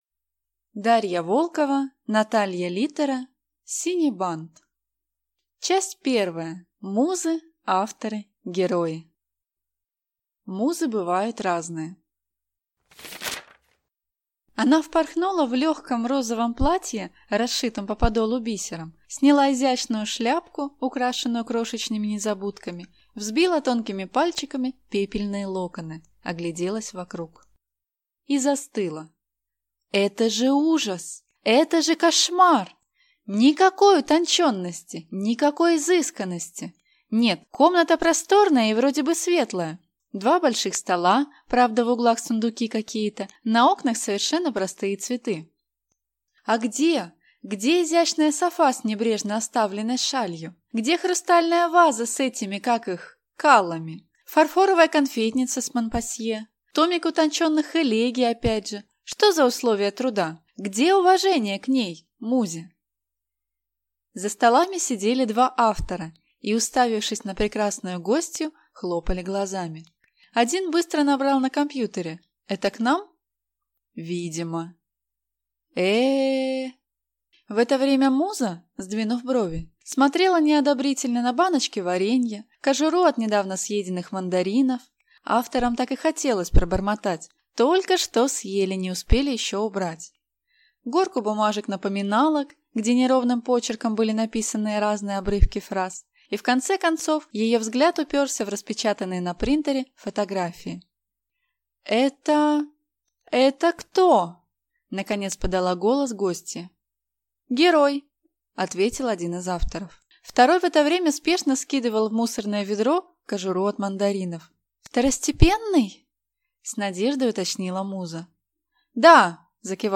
Аудиокнига Синий бант | Библиотека аудиокниг